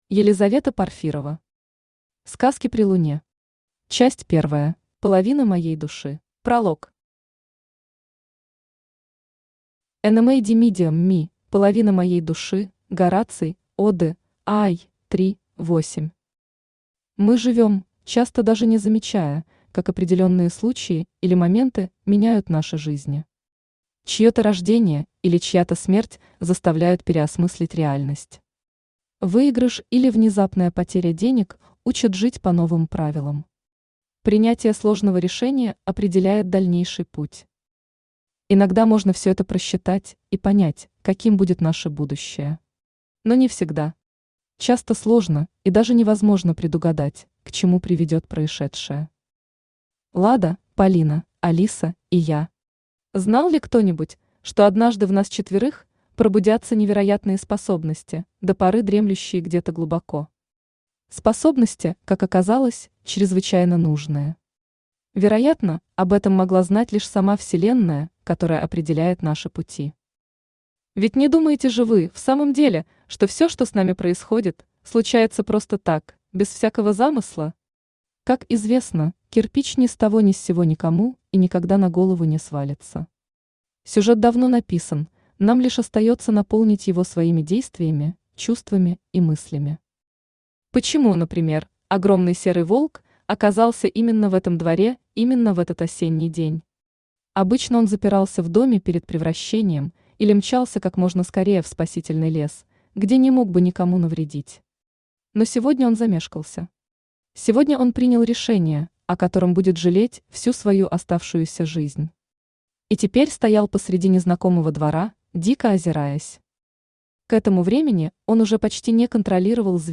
Аудиокнига Сказки при луне. Часть первая | Библиотека аудиокниг
Часть первая Автор Елизавета Порфирова Читает аудиокнигу Авточтец ЛитРес.